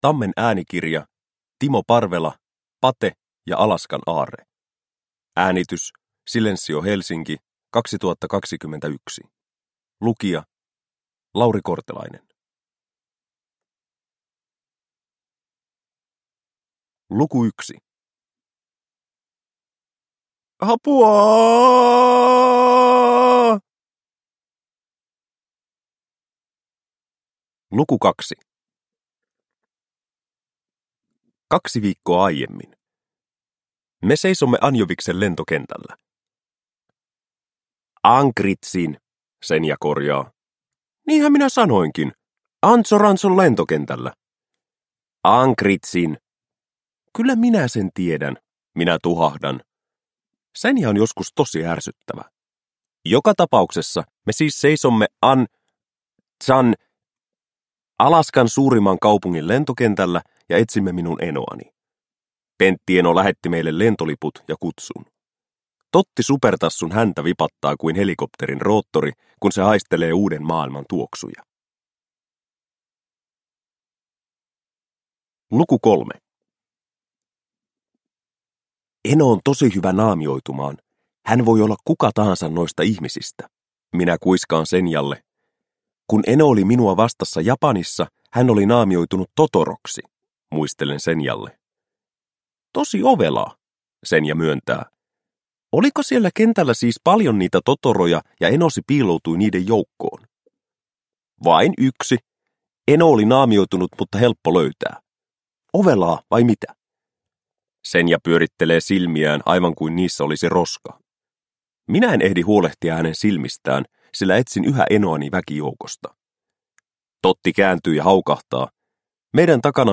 Pate ja Alaskan aarre – Ljudbok – Laddas ner